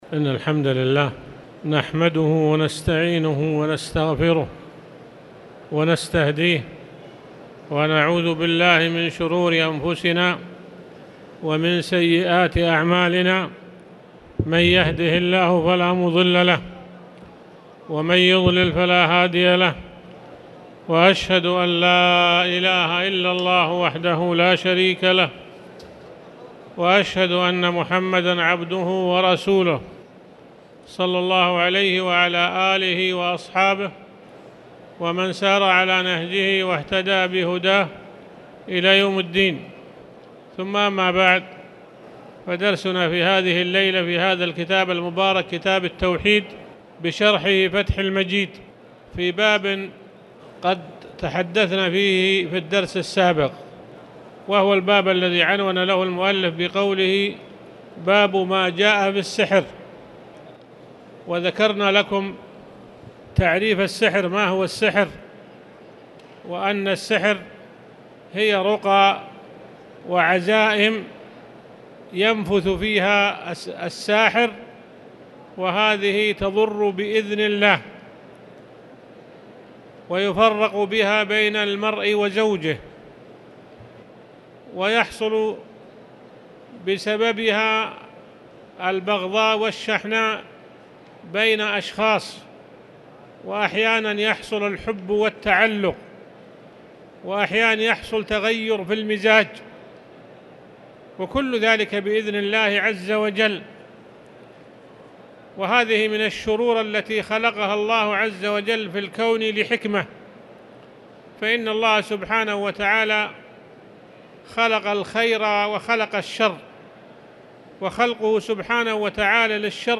تاريخ النشر ٢١ رجب ١٤٣٨ هـ المكان: المسجد الحرام الشيخ